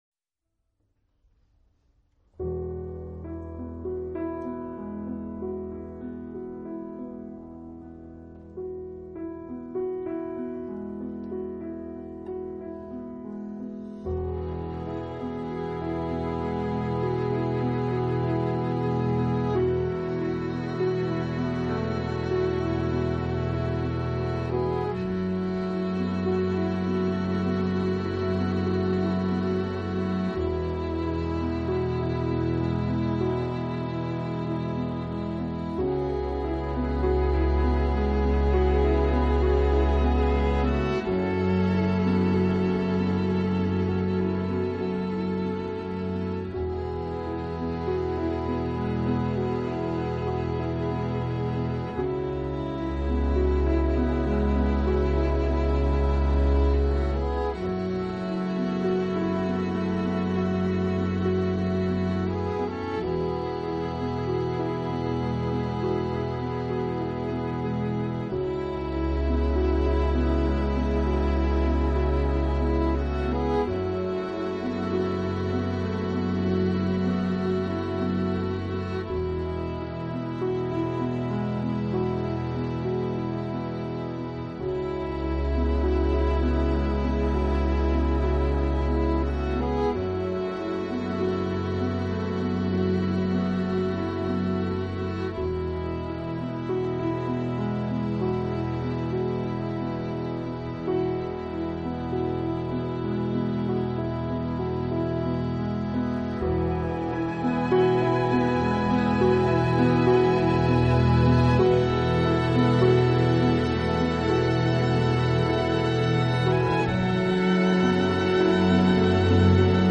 音乐类型：Soundtrack
这张专辑的音乐缓慢，阴暗而且神秘，就具有俄罗斯民族本身固有的特点。